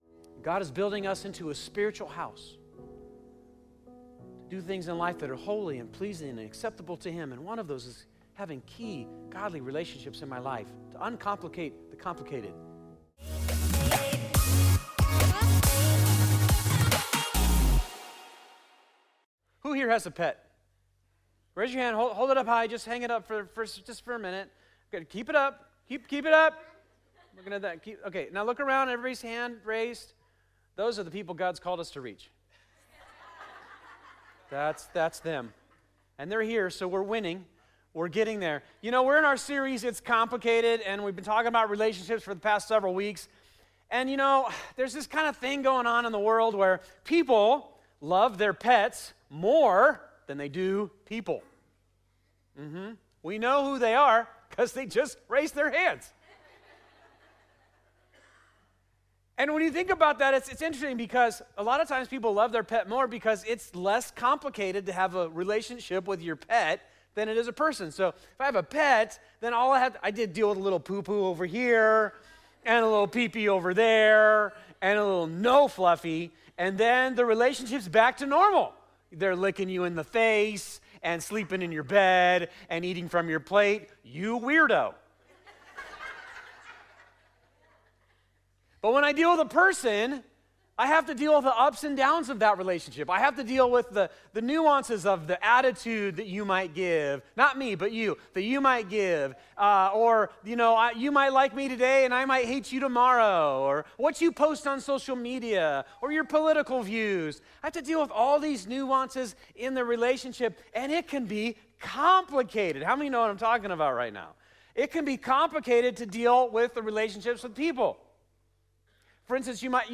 2026 It's Complicated Relationships Sunday Morning "It's Complicated" is our series at Fusion Christian Church on relationships.